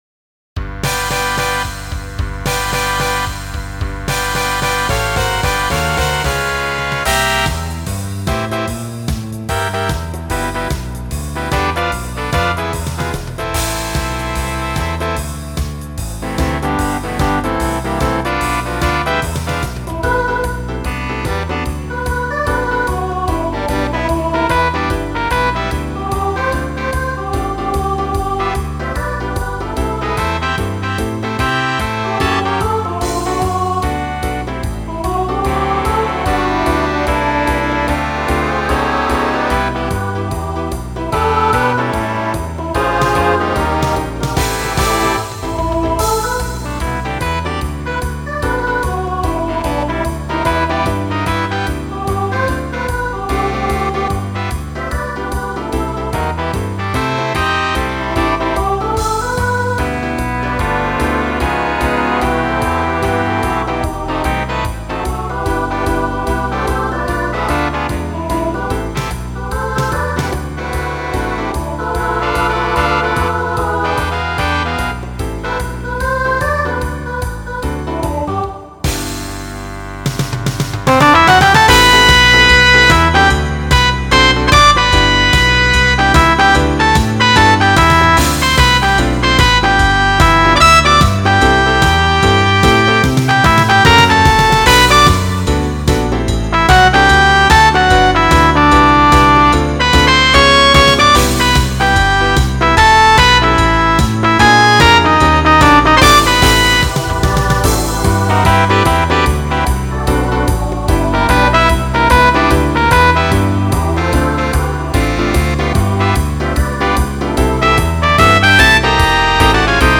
Voicing SSA Instrumental combo Genre Swing/Jazz
Mid-tempo